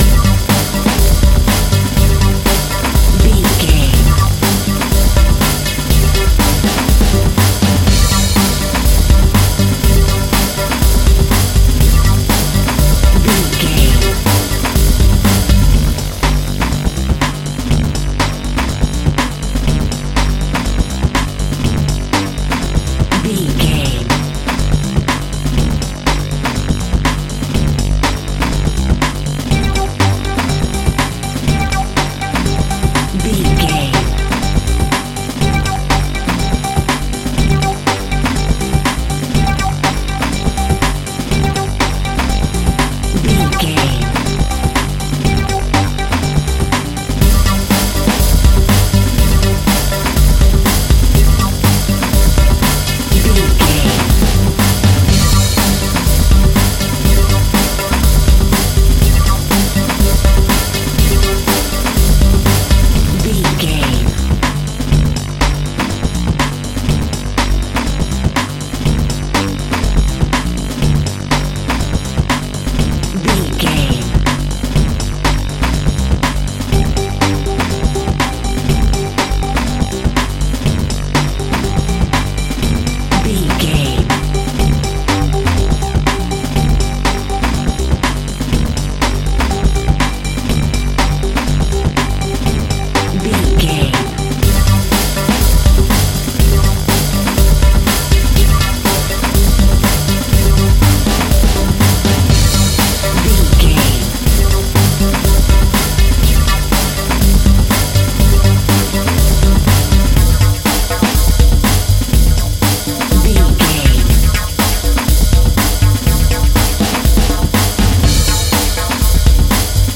Aeolian/Minor
aggressive
powerful
dark
groovy
futuristic
industrial
frantic
fast paced
synth pop
breakbeat
synthesizers
drums
bass guitar